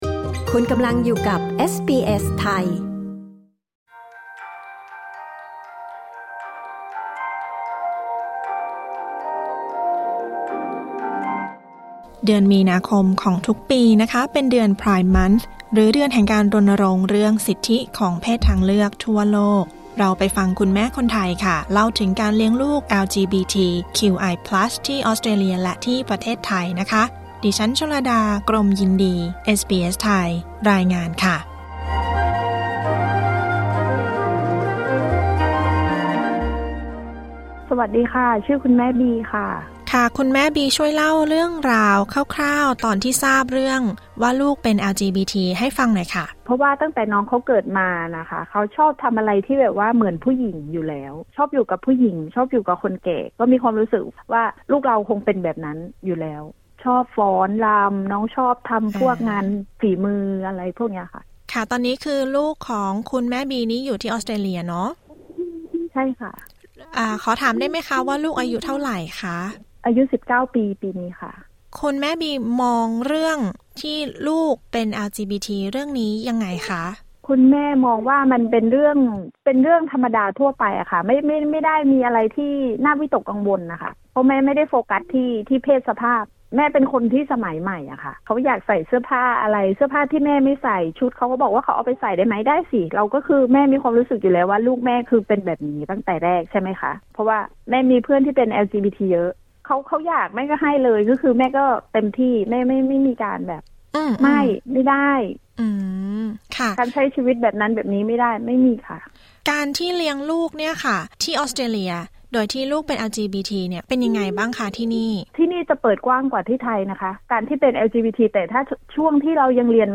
เดือนมิถุนายนเป็น Pride Month หรือเดือนแห่งการรณรงค์เรื่องสิทธิของเพศทางเลือกทั่วโลก ฟังคุณแม่คนไทยเล่าถึงการเลี้ยงลูก LGBTQI+ ที่ออสเตรเลียและที่ประเทศไทยด้วยความรักและความเข้าใจ